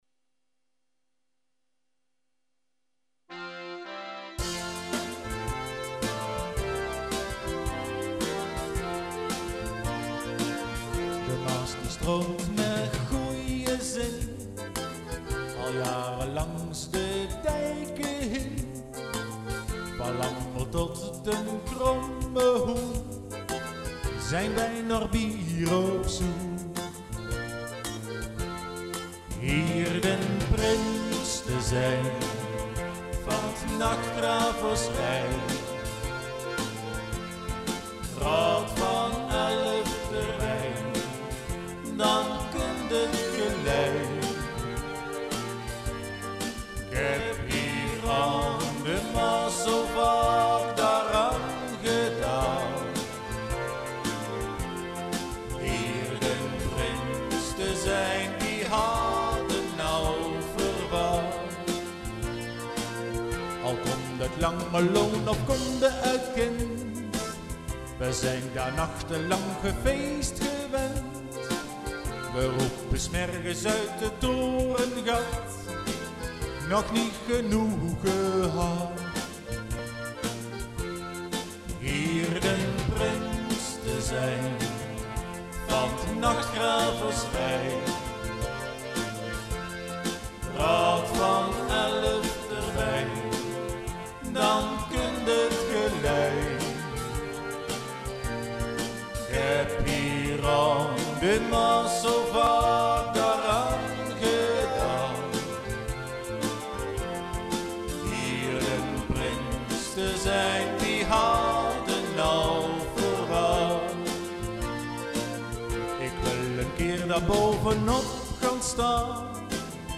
carnavals liedjes